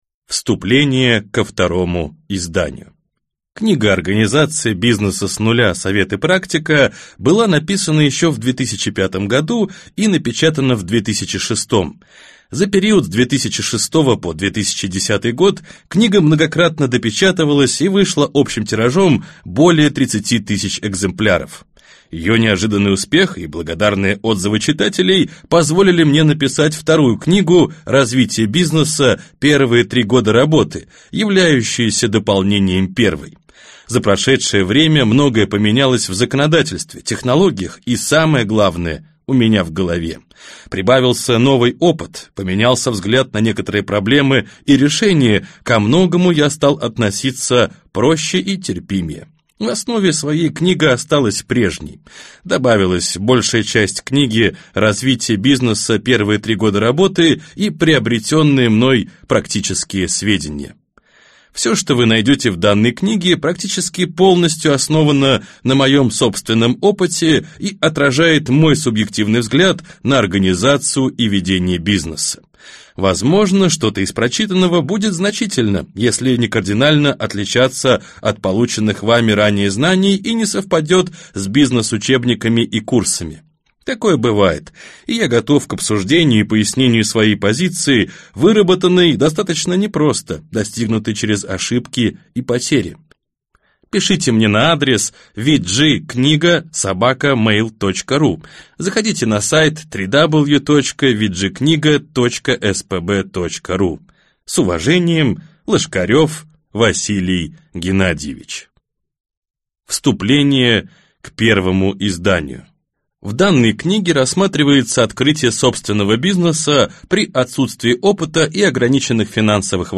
Аудиокнига Бизнес с нуля.